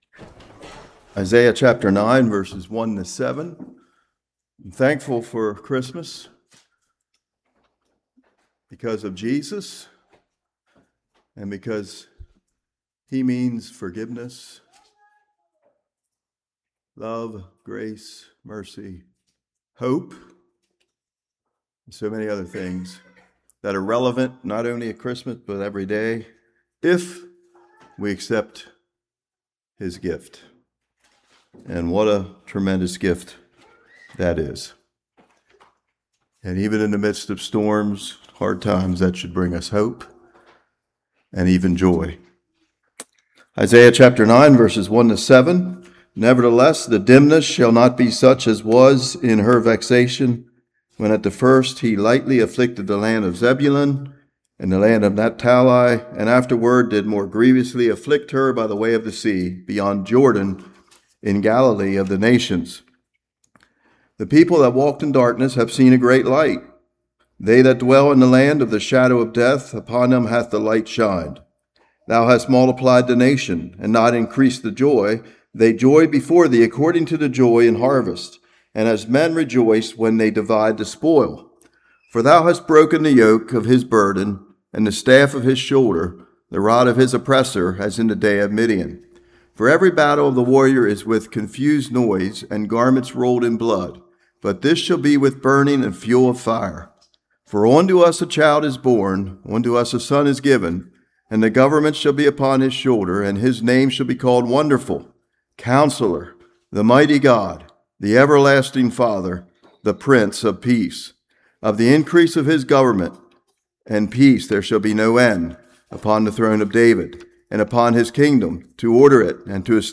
Isaiah 9:1-7 Service Type: Morning History of what is going on in the beginning of the chapter.